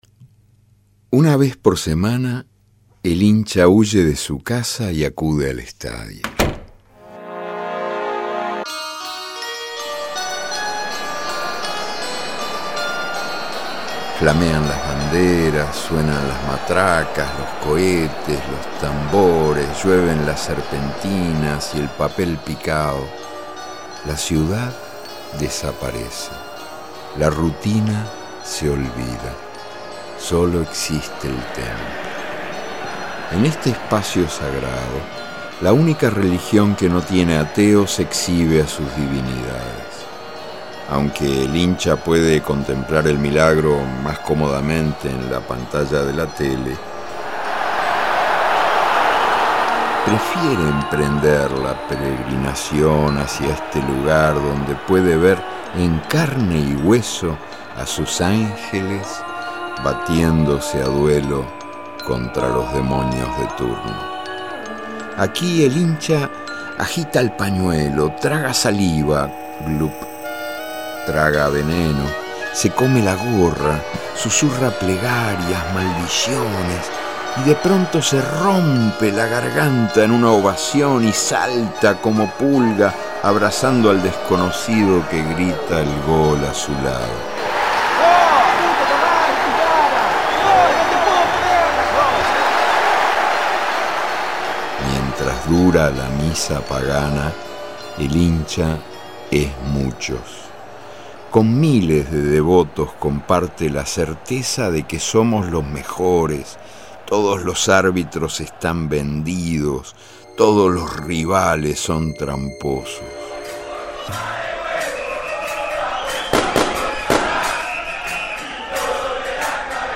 Título: El hincha según Eduardo Galeano. Género: Ensayo. Sinopsis: Eduardo Galeano ensaya un relato antropológico de los comportamientos de los hinchas de fútbol.
4:25 Autor: Eduardo Galeano (texto e interpretación)